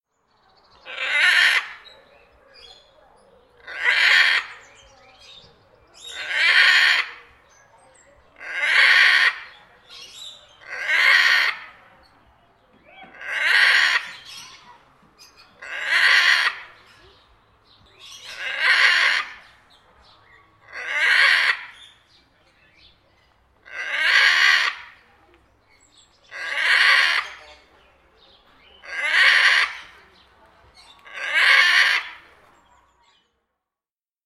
دانلود صدای طوطی از ساعد نیوز با لینک مستقیم و کیفیت بالا
جلوه های صوتی
برچسب: دانلود آهنگ های افکت صوتی انسان و موجودات زنده